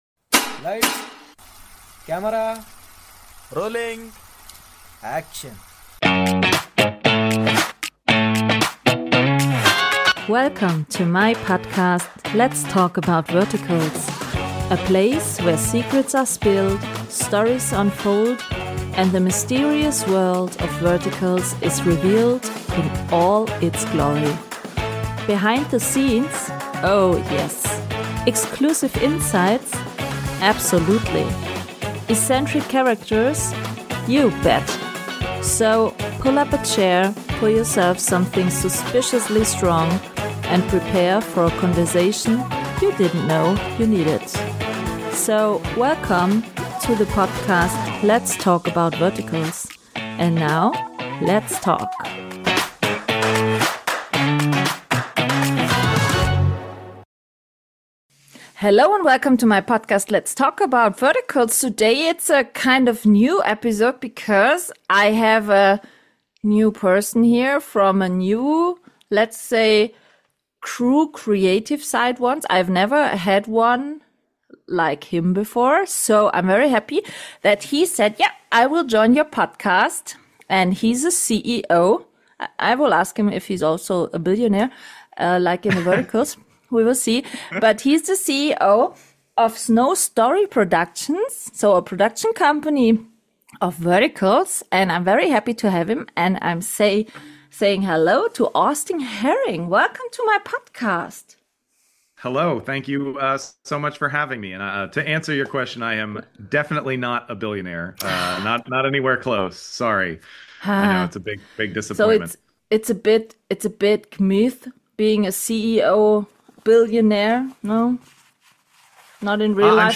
Let’s just say… it sounds more than promising A bold vision, a creative collaboration, and maybe the start of something truly spectacular This episode offers a behind-the-camera look at vertical production like never before on the podcast  — packed with valuable insights, honest reflections, and an engaging, entertaining conversation.